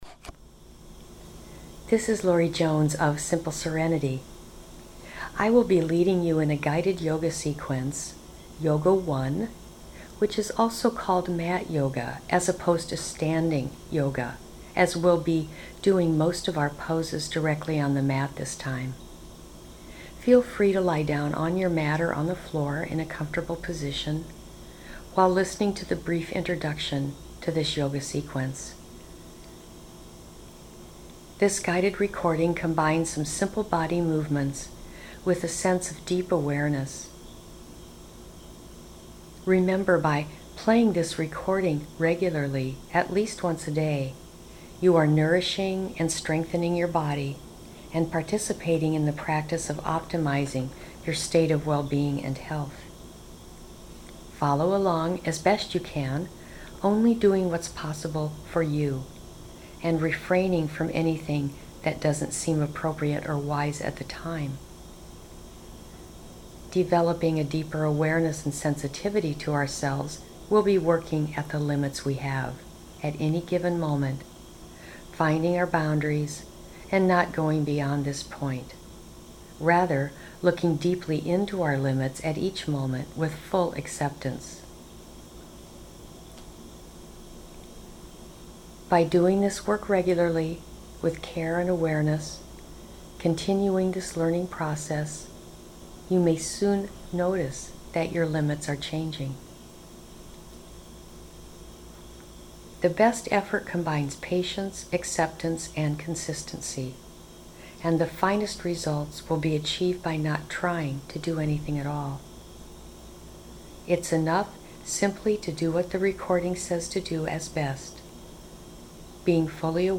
02guided-yoga1-sequence.mp3